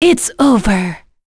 Pavel-Vox_Victory_b.wav